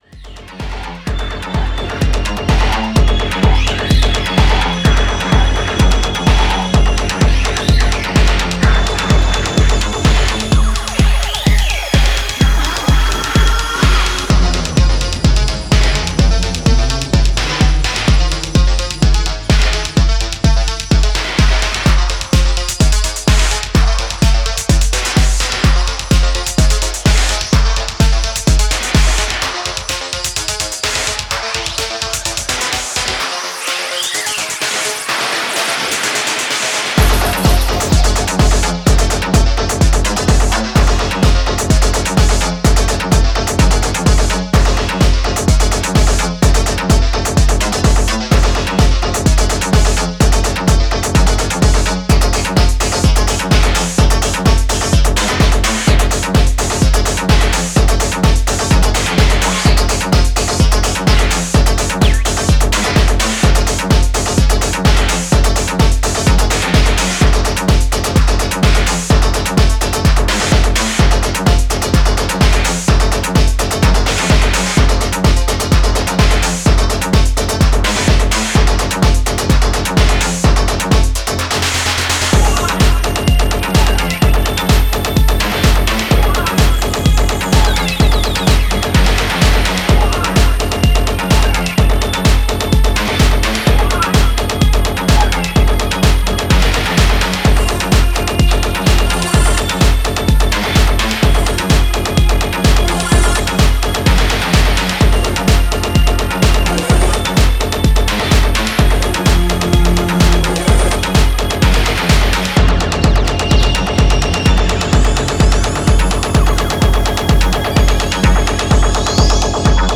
supplier of essential dance music